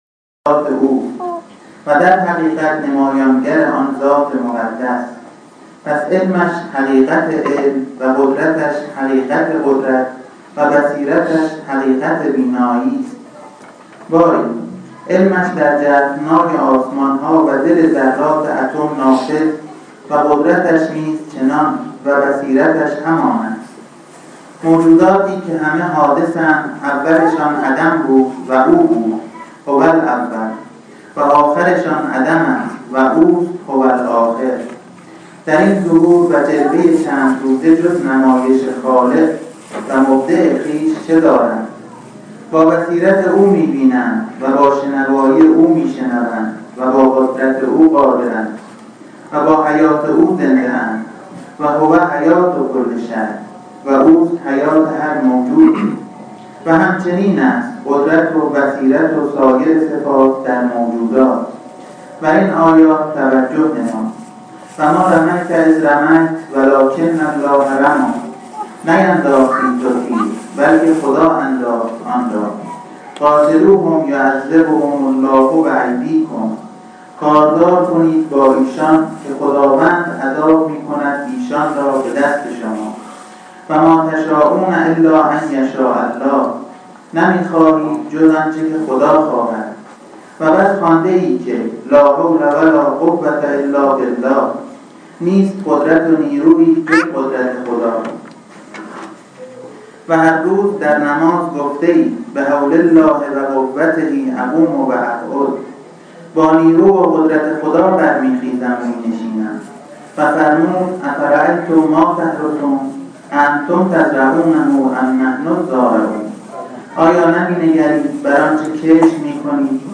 سخنرانی چهارشنبه ،92/7/9(تجلی6 معرفت افاق)